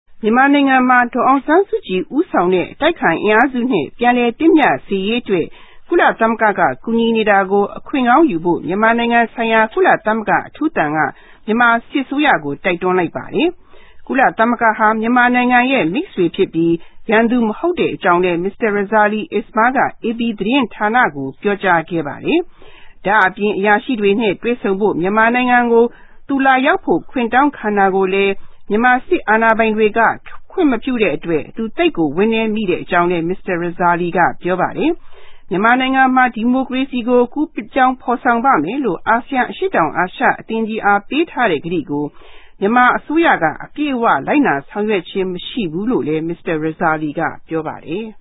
Listen to the news in Burmese, 130KB.